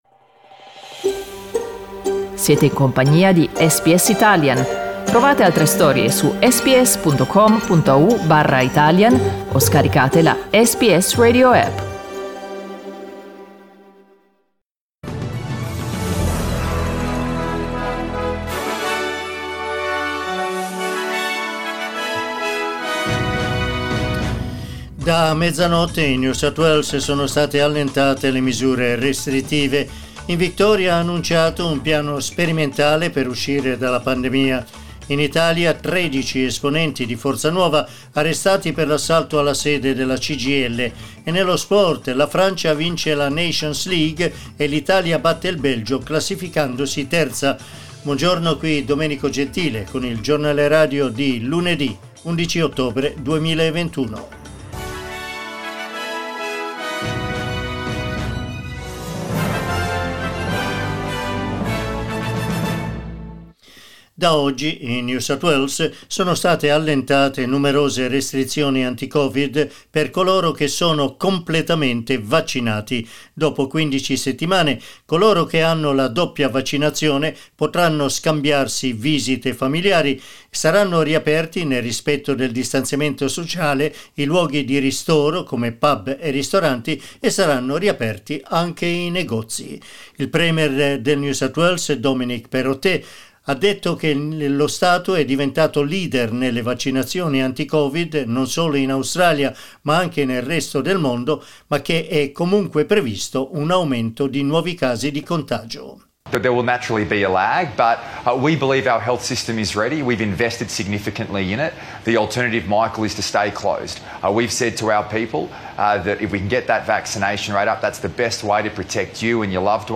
Giornale radio lunedì 11 ottobre 2021
Il notiziario di SBS in italiano.